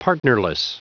Prononciation du mot partnerless en anglais (fichier audio)
Prononciation du mot : partnerless